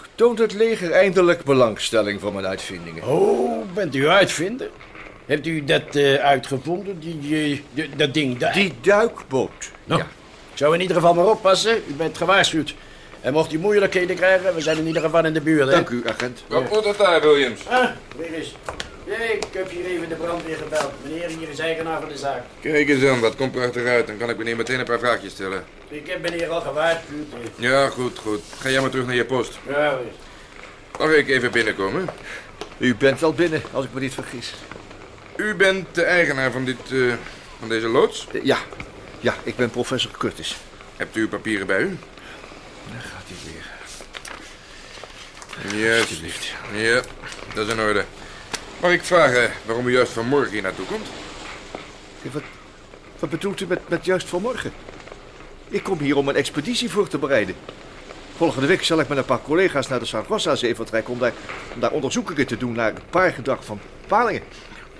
Jan Borkus – speelt professor Curtis